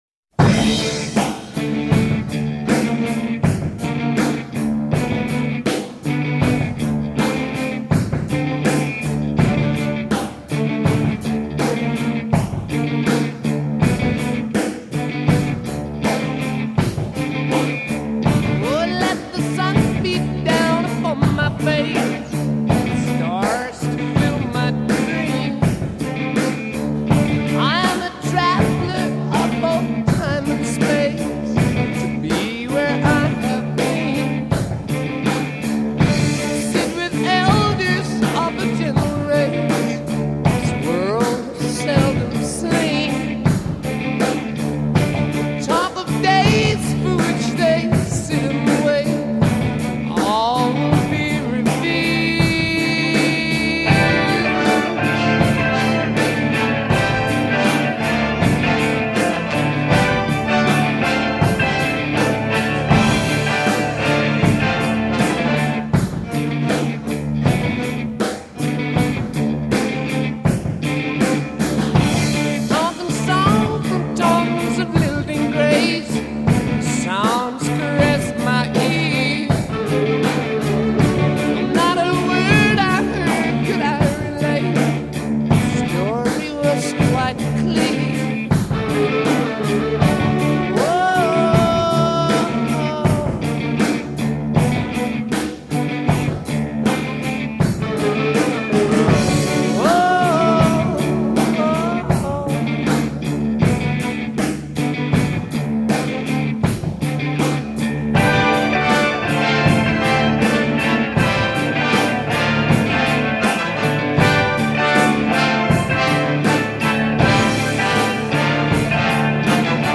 hard rock
guitare
chant
basse, claviers
batterie